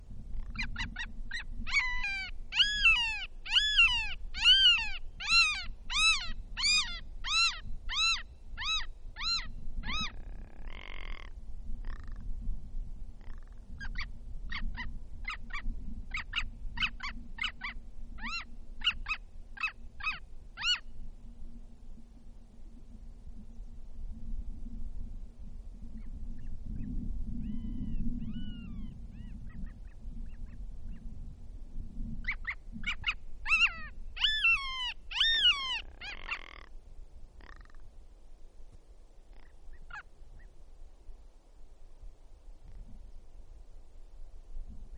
Long-tailed Jaegers produce their harmonic-rich flight calls near the rocky tor features they prefer to nest upon. Wind is also audible along the otherwise smooth landscape of the Sushana Ridge, Denali National Park.